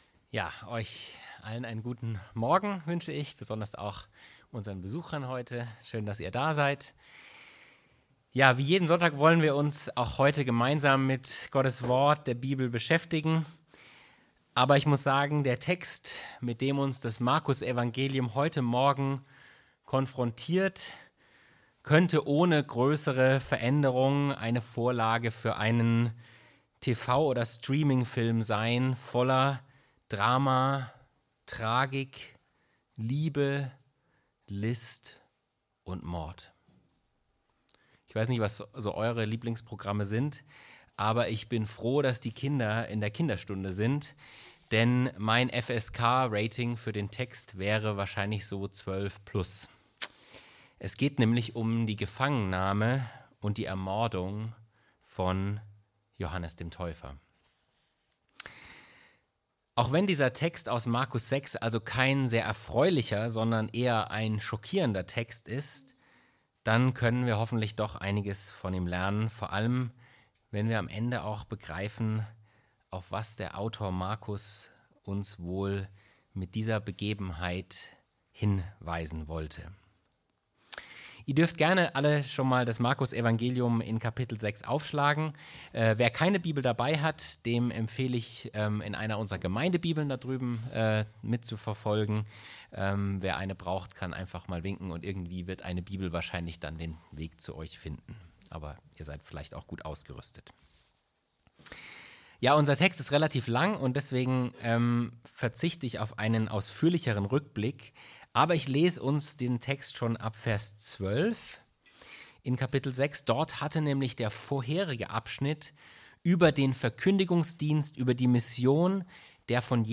Predigtreihe: Markusevangelium Auslegungsreihe